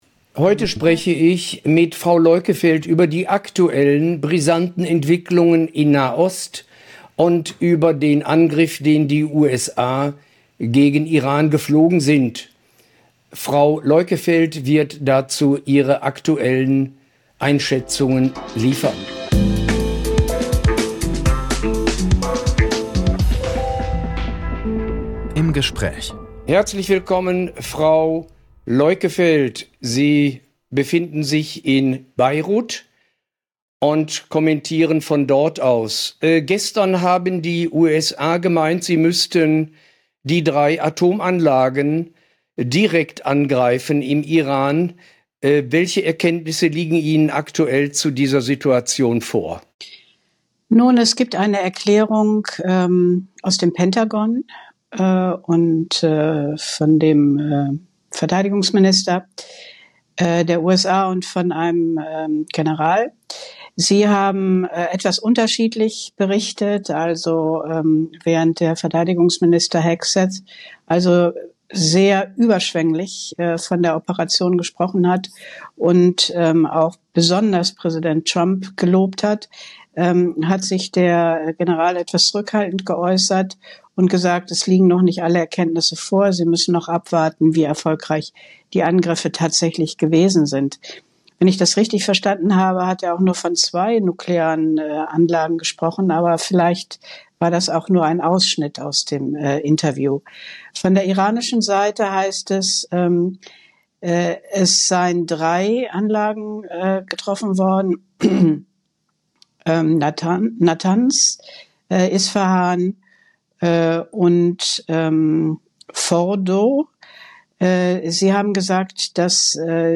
Video-Interview
Der Beitrag ist auch als Audiopodcast verfügbar